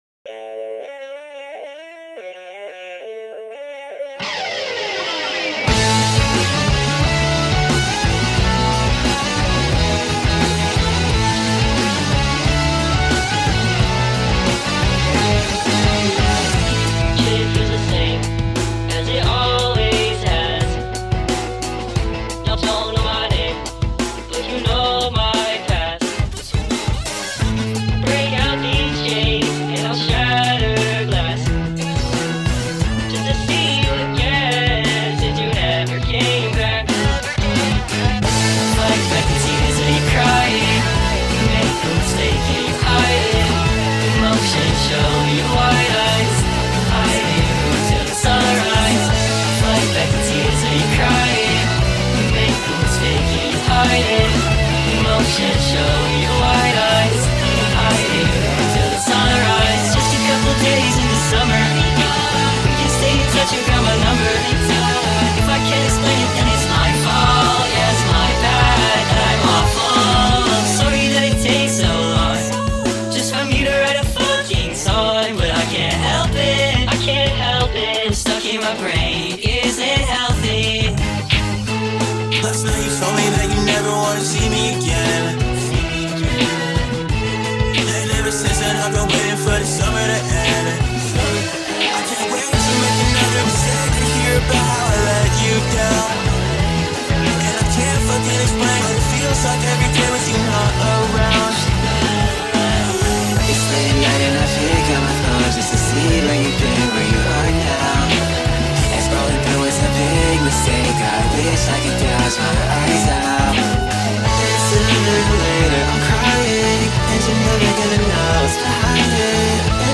Music / Rock
mashup